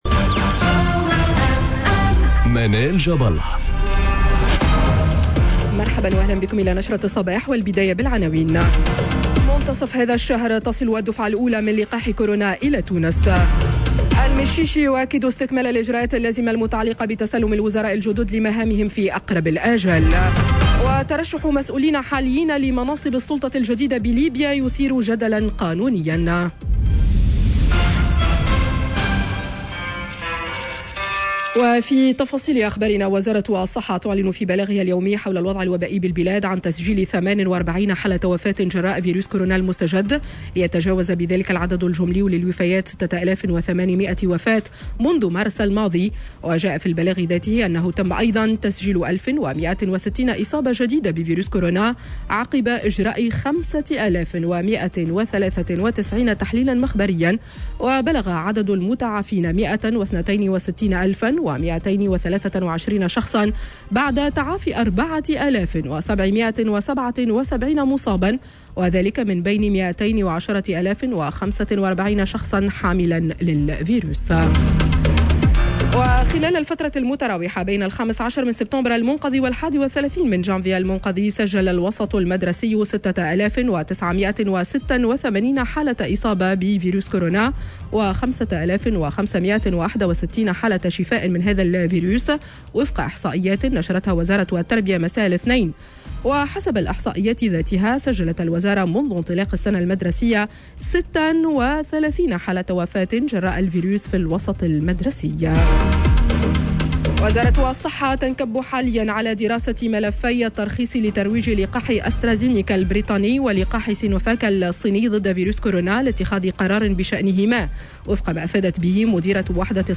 نشرة أخبار السابعة صباحا ليوم الثلاثاء 02 فيفري 2021